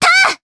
Cleo-Vox_Attack1_jp.wav